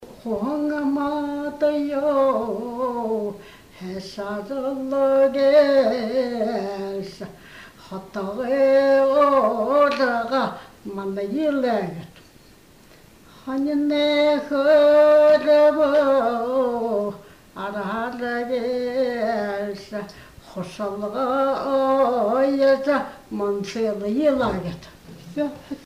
01 Колыбельная песня (Улгын дуун
Место фиксации: Иркутская область, Боханский район, село Тараса Год